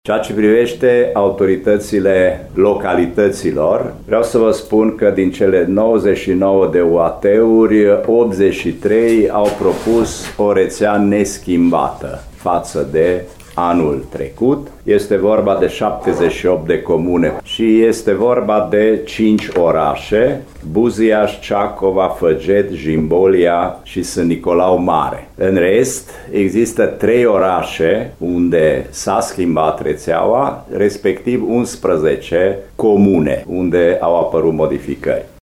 Cele câteva modificări de anul viitor apar doar în mediul privat şi la învăţământul preşcolar, spune inspectorul școlar general adjunct, Francis Halasz.